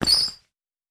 Court Squeak Sudden Stop.wav